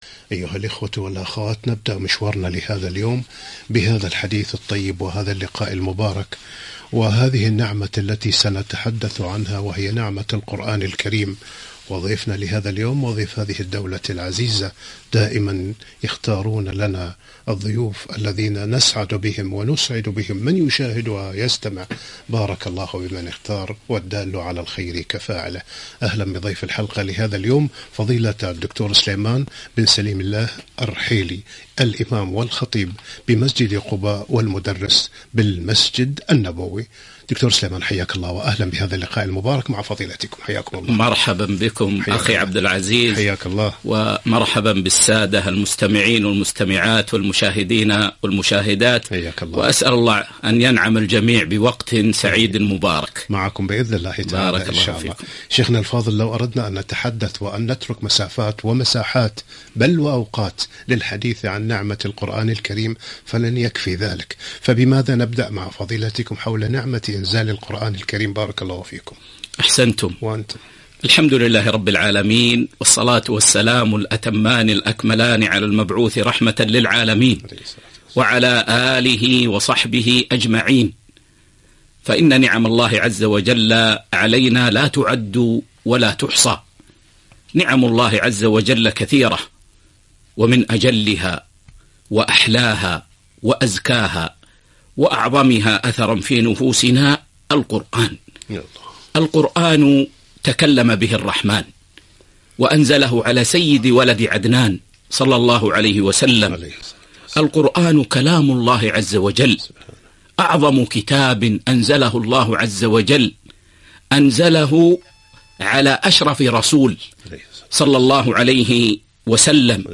لقاء إذاعي ( نعمة القرآن )